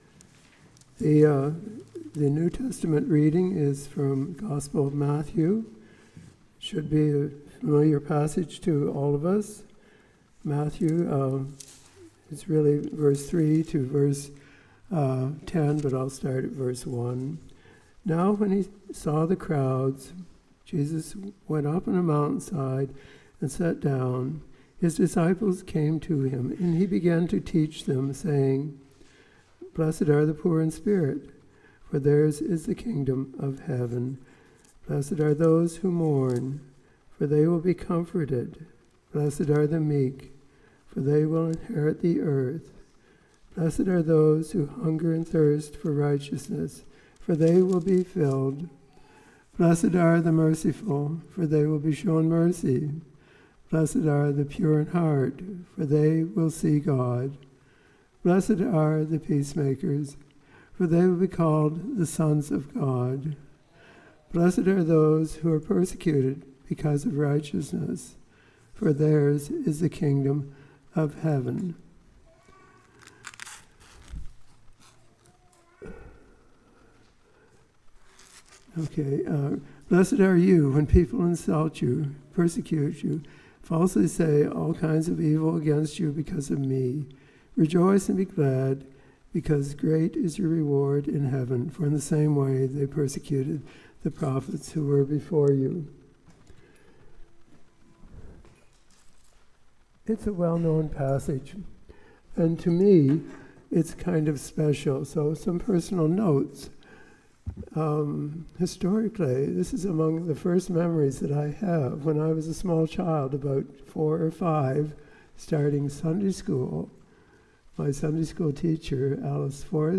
Sermon “Blessing the Empty